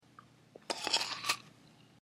jablko_suche.mp3